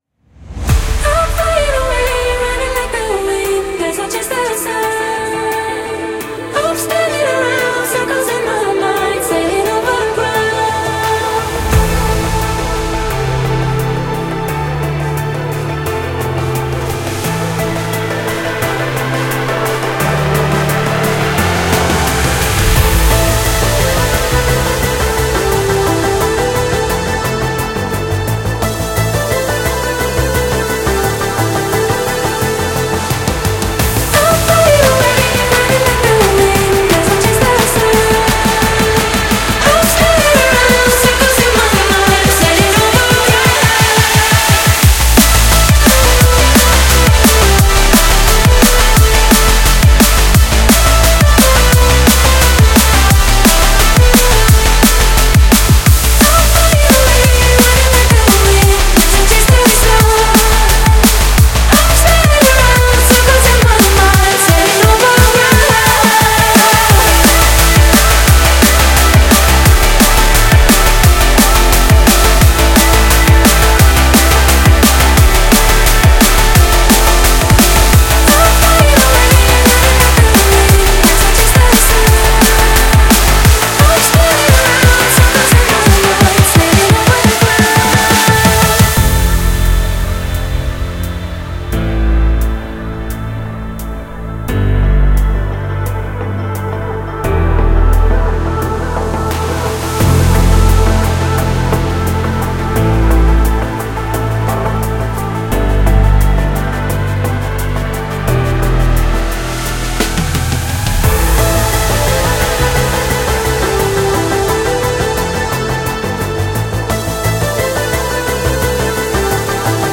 BPM174-174
Audio QualityPerfect (High Quality)
Drum and Bass song for StepMania, ITGmania, Project Outfox
Full Length Song (not arcade length cut)